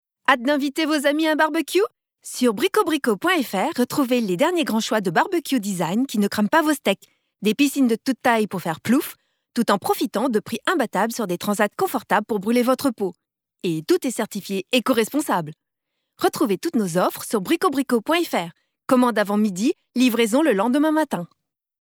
Voix off
5 - 53 ans - Mezzo-soprano